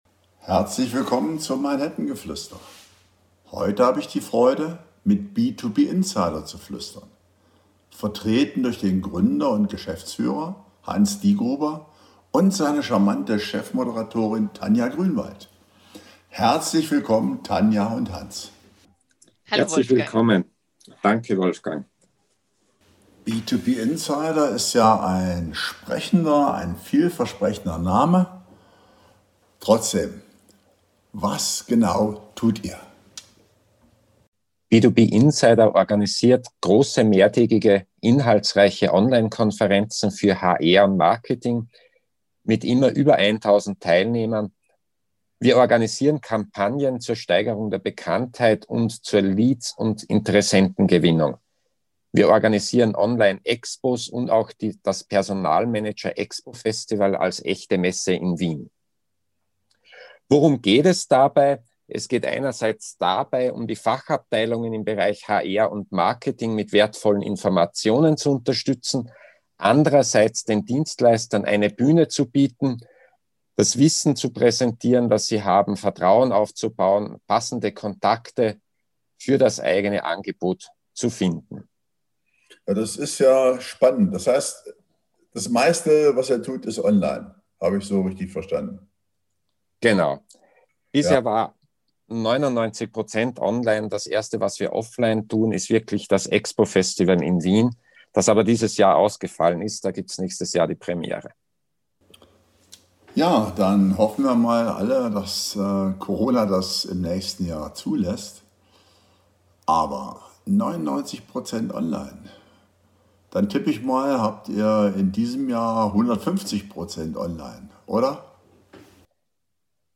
B2B Insider, ein Marktführer für Online-Konferenzen, im Gespräch.